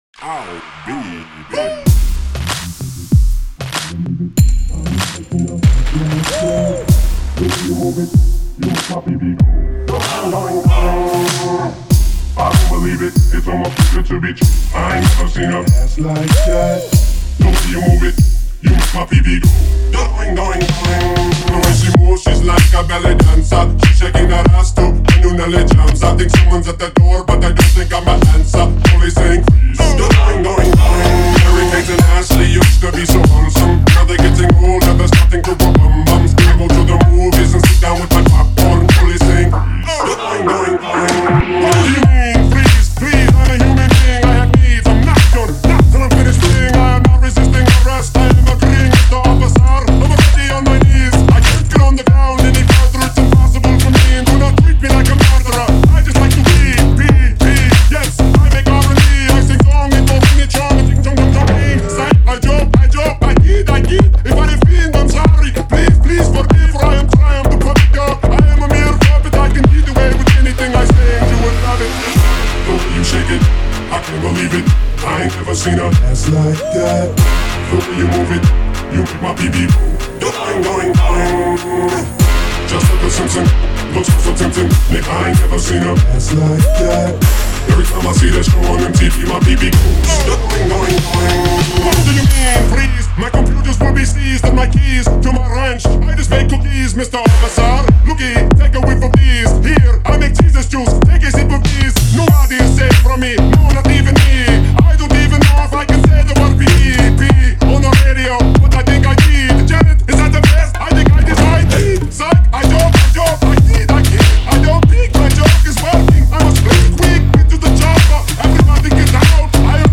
это энергичная хип-хоп композиция